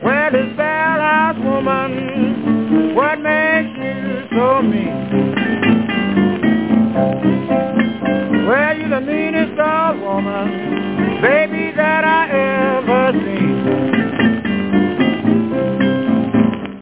вокал, пианино
гитара